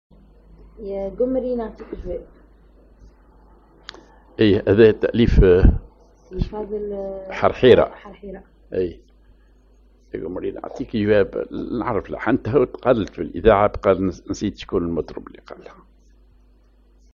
Maqam ar نوا تونسي
Rhythm ar مدور تونسي
genre أغنية